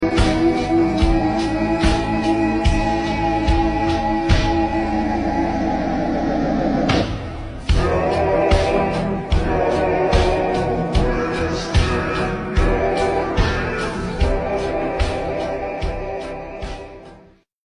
Boeing 737 800 ..The worst plane sound effects free download